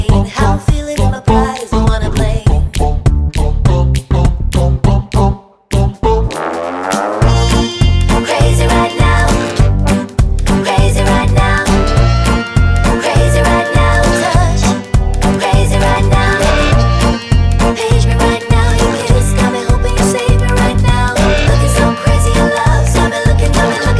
no Backing Vocals Jazz / Swing 3:55 Buy £1.50